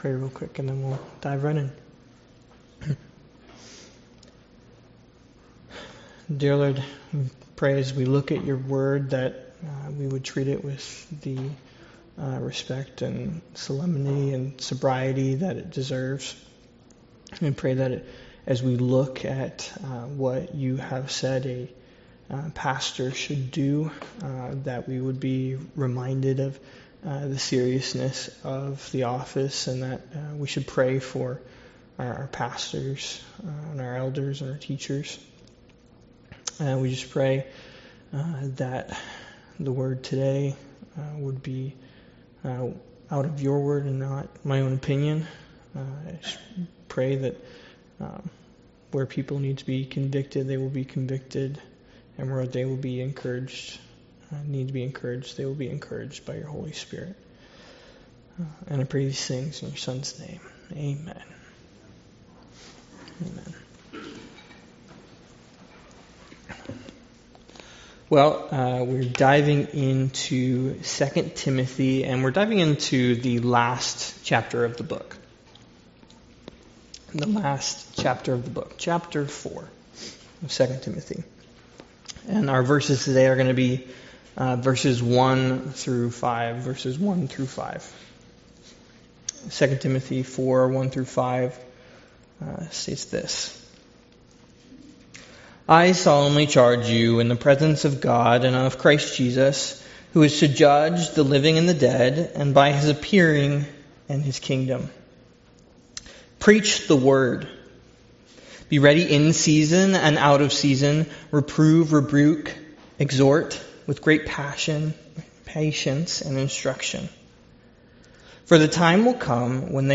Studies in Exodus Passage: Exodus 14 Service Type: Sunday School « Sorrento